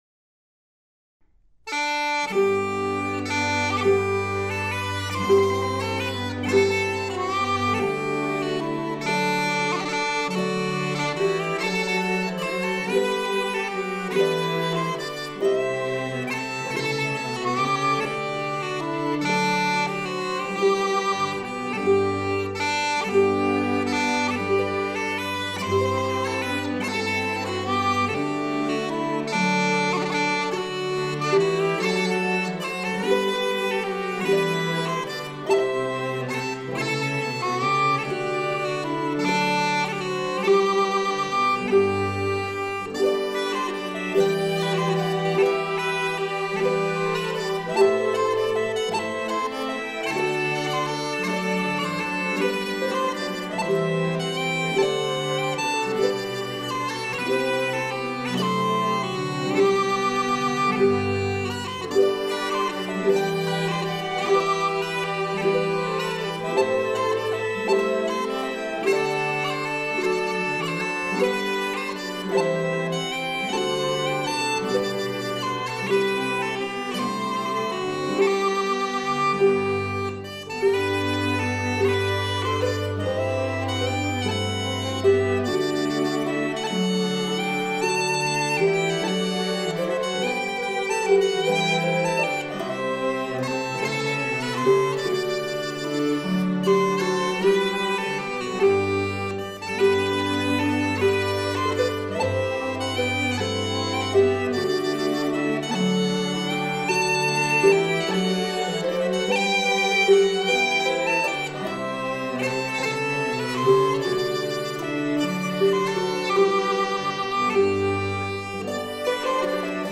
Cello
Violin
Uillean pipes, whistle
Harp